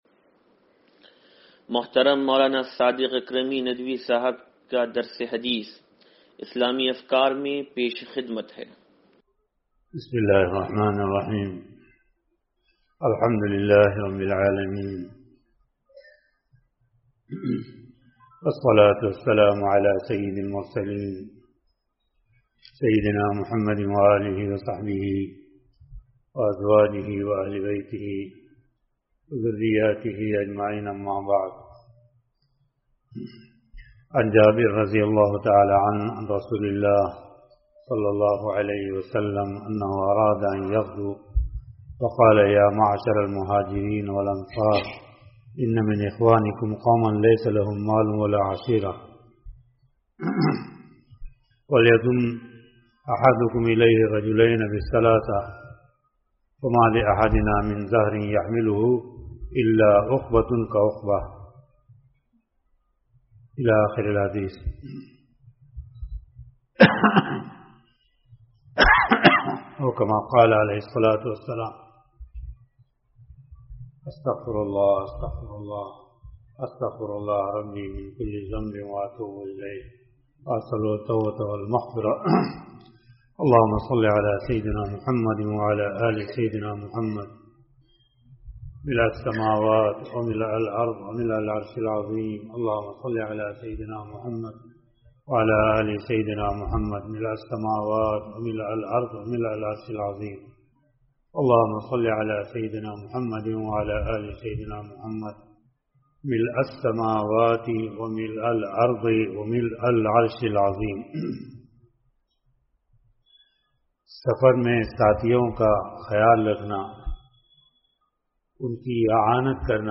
درس حدیث نمبر 0758
سلطانی مسجد